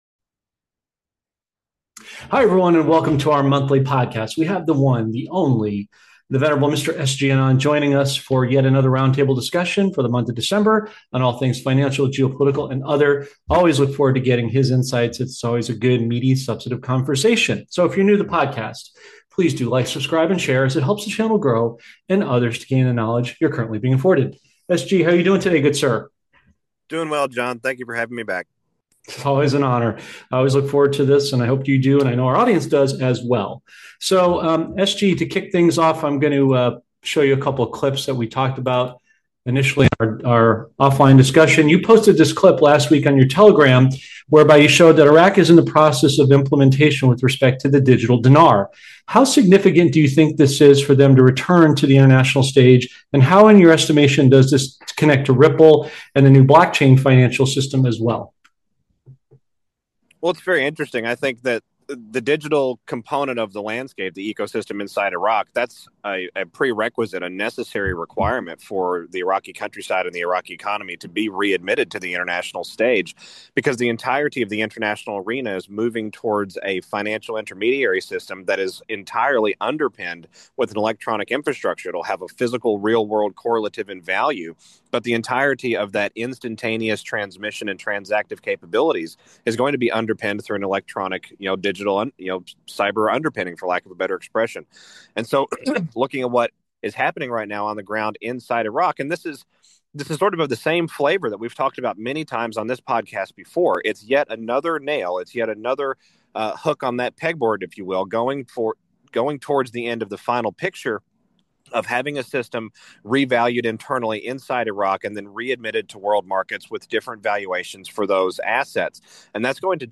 In this podcast, the host and guest discuss the significant changes happening in Iraq’s financial system, particularly the implementation of a digital currency, the digital dinar.